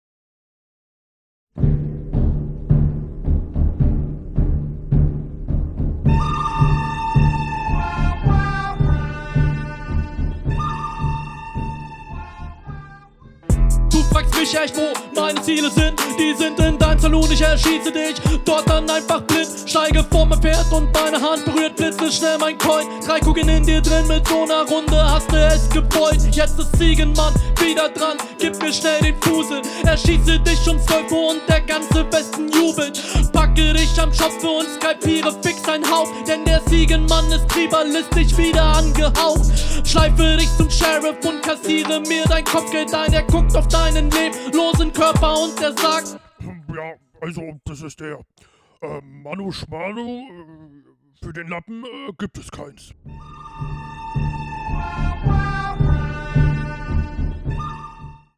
Intro geil, aber der Übergang zum Beat nicht so smooth.